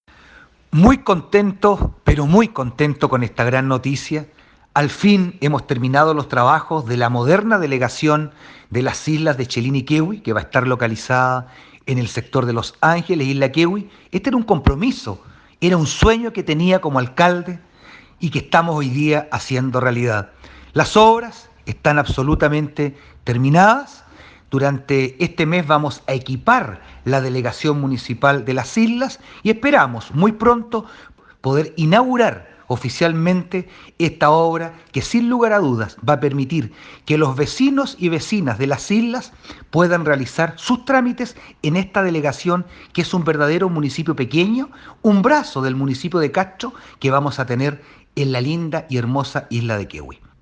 cuña-1-alcalde-vera-tema-delegacion-municipal.mp3